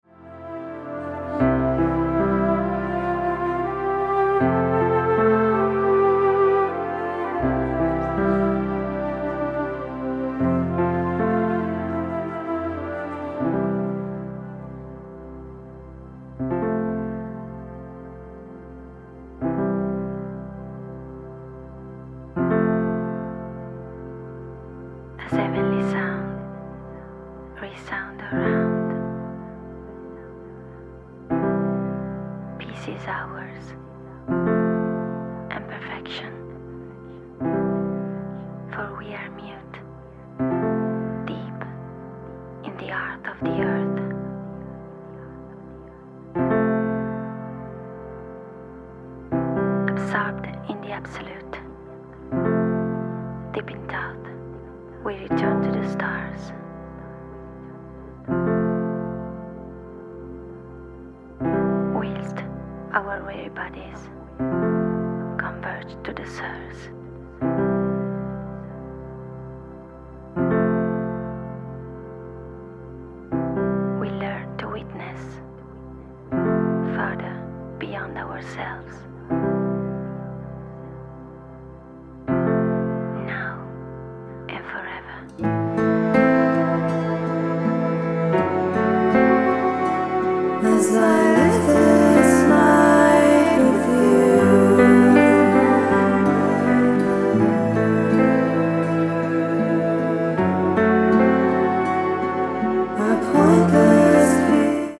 mandolins, guitar, bass, piano, keyboards, percussion
spoken word and vocals